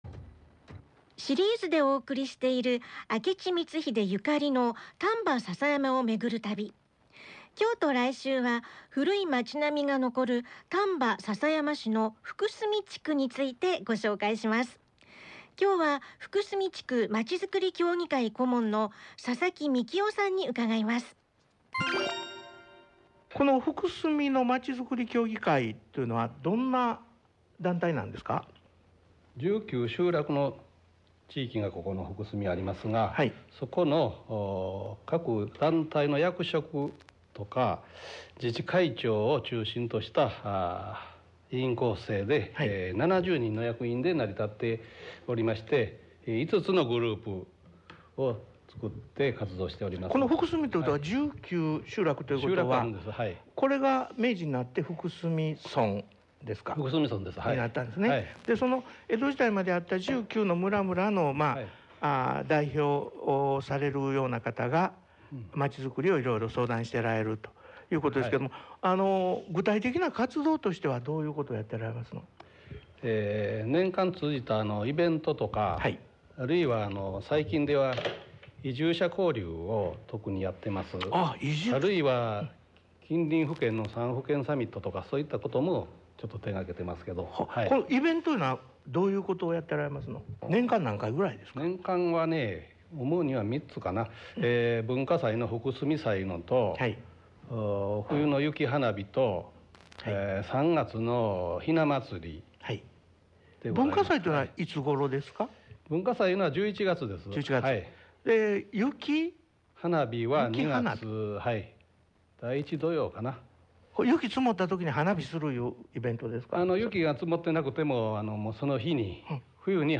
『ラジオで辿る光秀ゆかりの兵庫丹波』2020年8⽉27⽇放送回音声